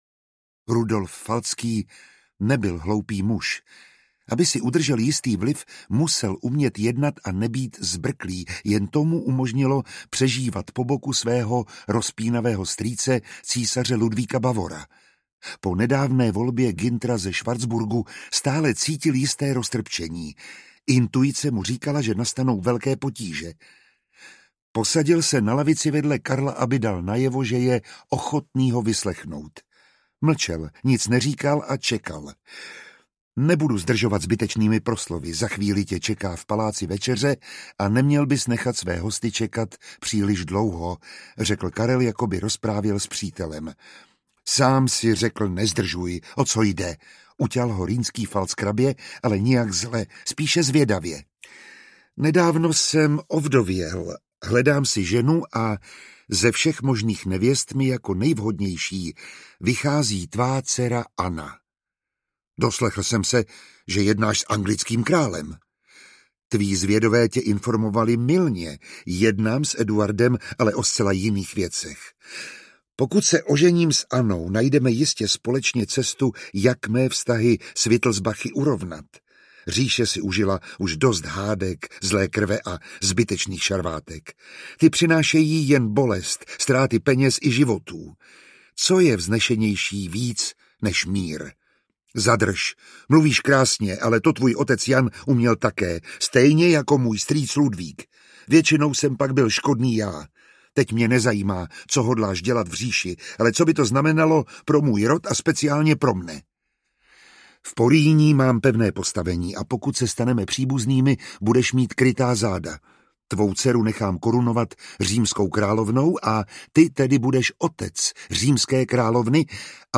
Audiobook
Read: Miroslav Táborský